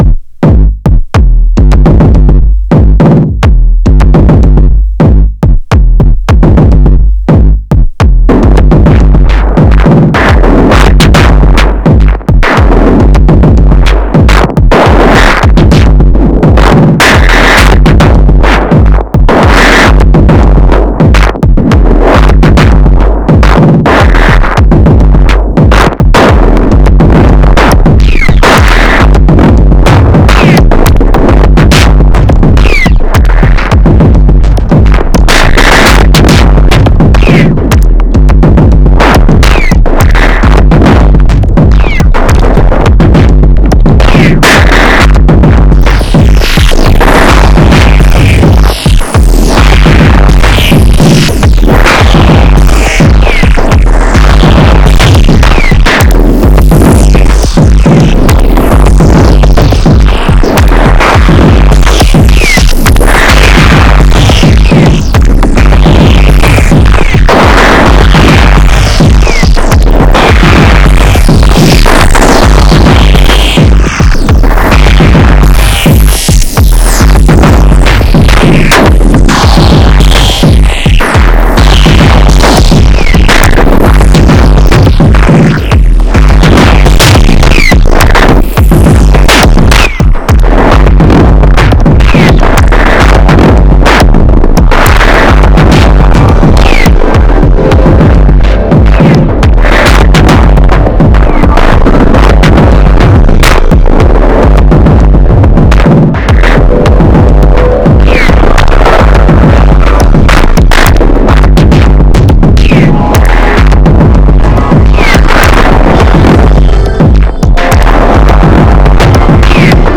Three subtracks for kicks, snares, and percussion.
One single track of a pad sample, and one of a “noise”.
Lots of trig probabilities for steps - very fun.